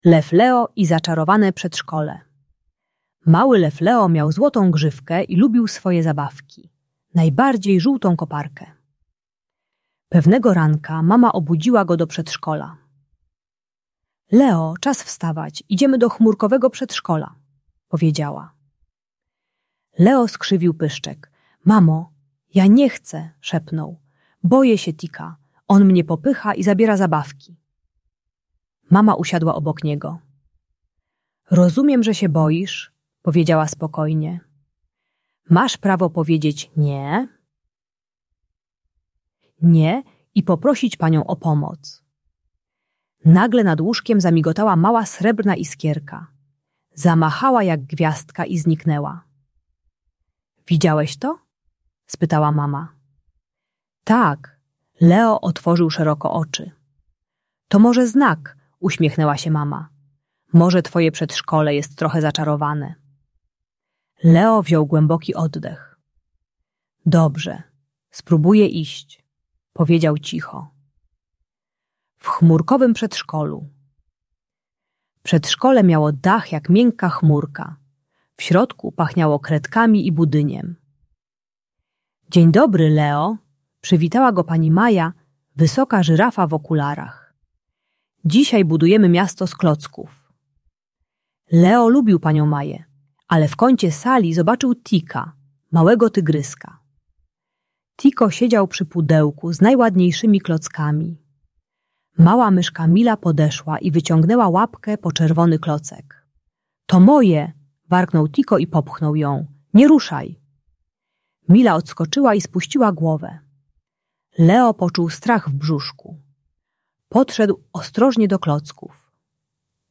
Leo i Zaczarowane Przedszkole - Przedszkole | Audiobajka